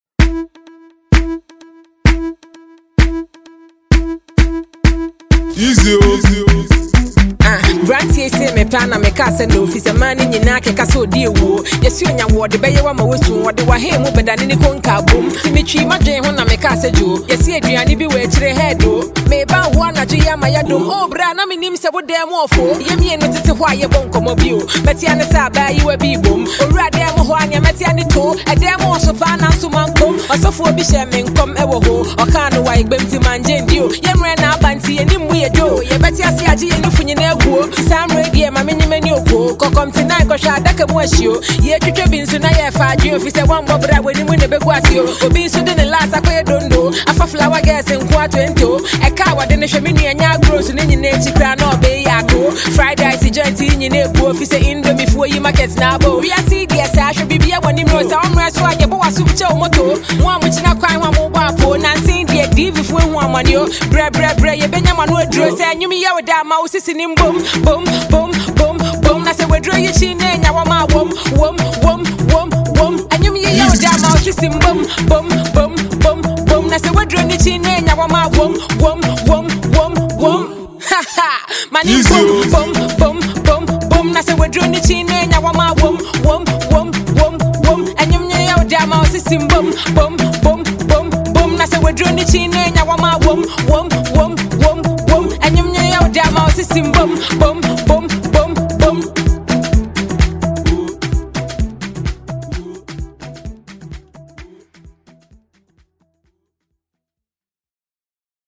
female lyricist
She laid rhyming and heavy bars
Not a bad remix tho, take a listen below.